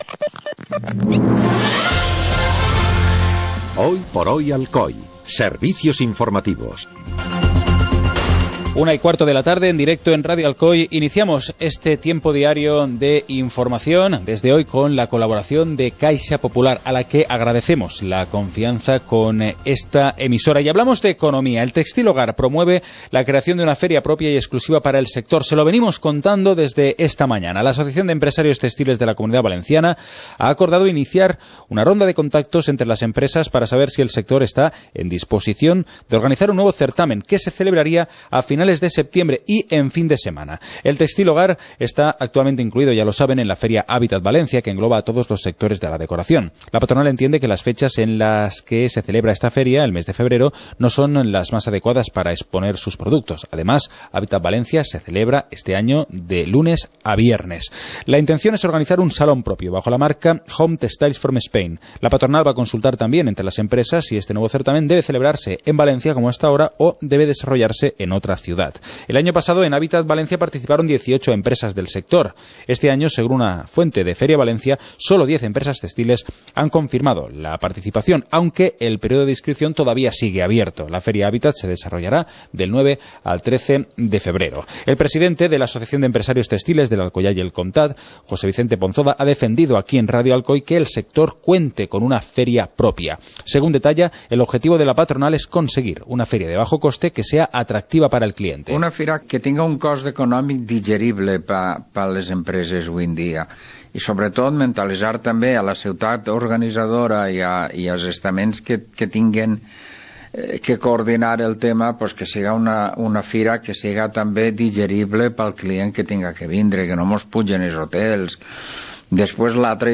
Informativo comarcal - lunes, 12 de enero de 2015